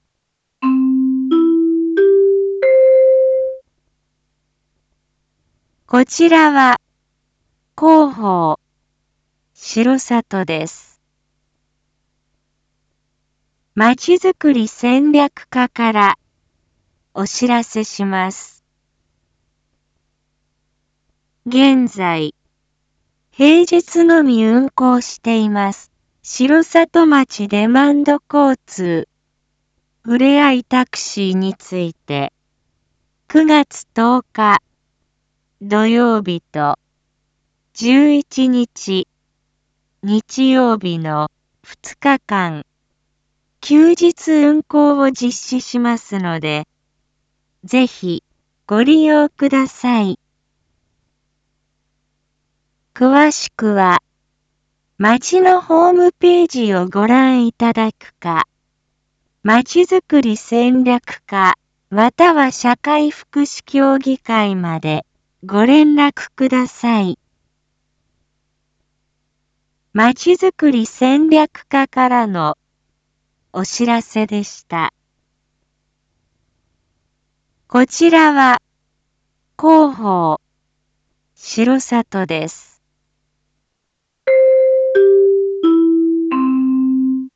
一般放送情報
Back Home 一般放送情報 音声放送 再生 一般放送情報 登録日時：2022-09-08 19:01:28 タイトル：R4.9.8 19時放送分 インフォメーション：こちらは広報しろさとです。